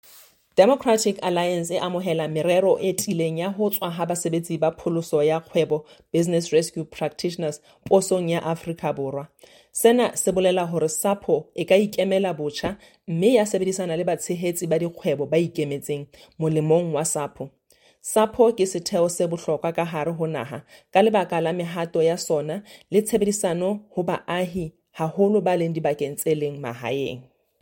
Sesotho soundbites by Tsholofelo Bodlani MP